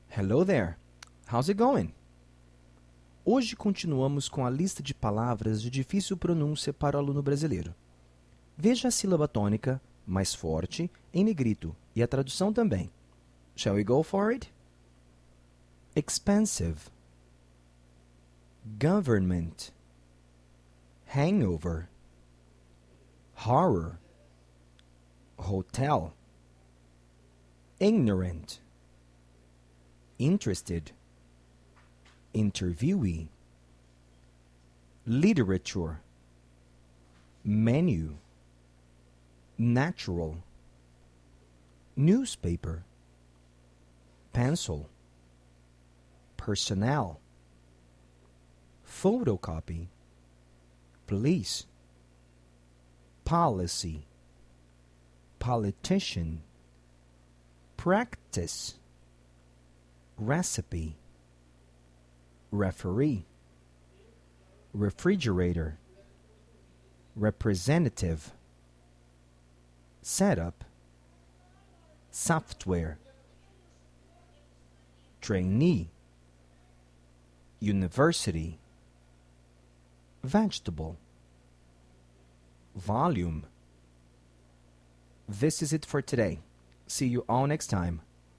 pronunciation-difficult-sounds-02.mp3